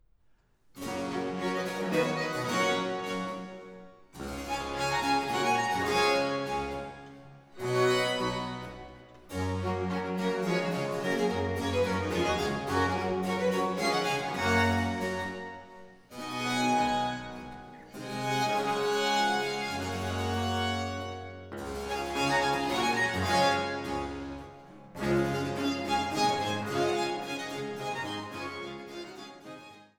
Violoncello
Theorbe
Cembalo, Polygonalspinet